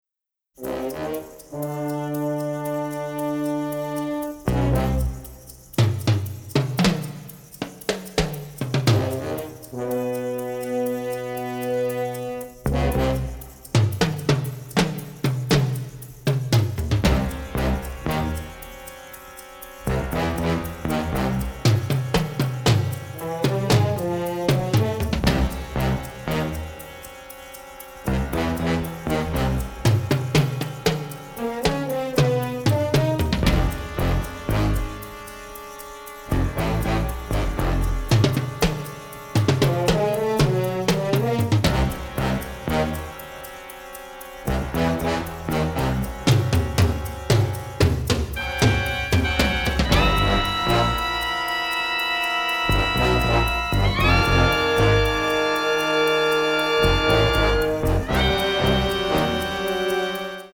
funk/jazz/groove